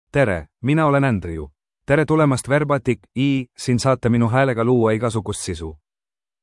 AndrewMale Estonian AI voice
Voice sample
Male
Andrew delivers clear pronunciation with authentic Estonia Estonian intonation, making your content sound professionally produced.